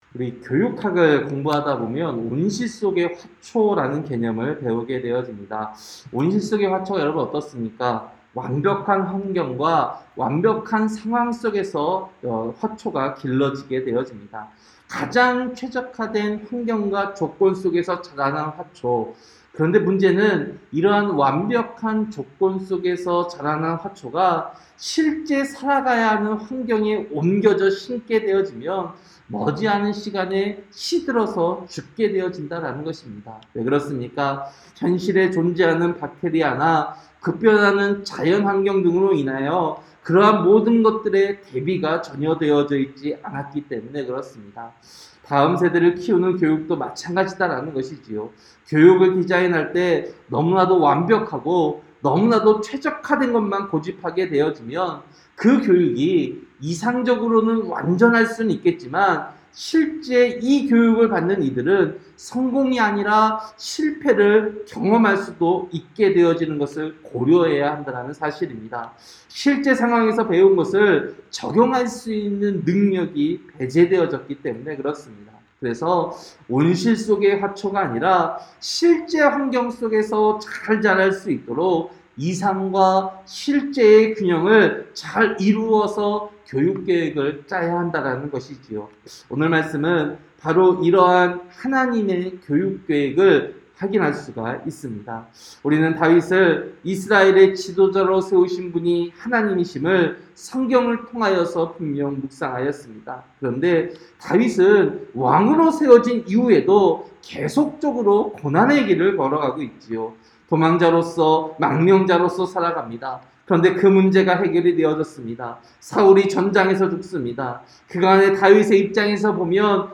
(새벽설교) 사무엘하 3장